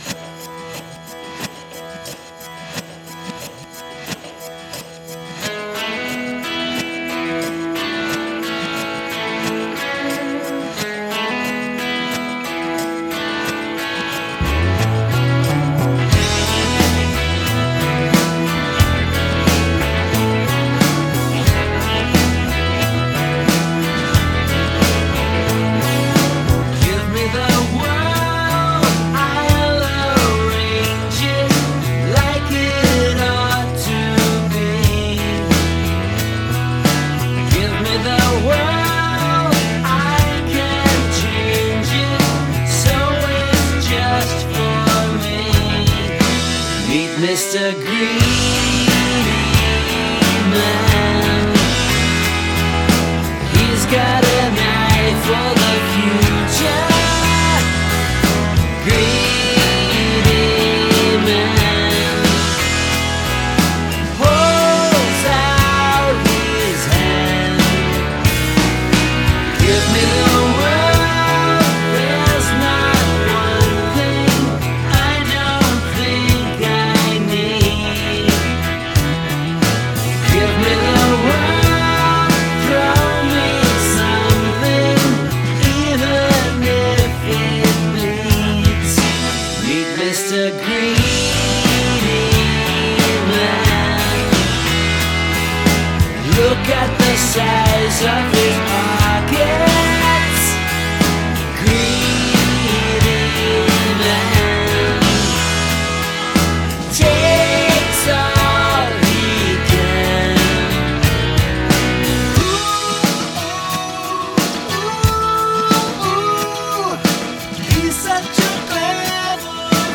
It is another truly shameless rip-off, but enjoyable.
They just want to sound like The Beatles.
Amazingly Beatles-esque, I’d say.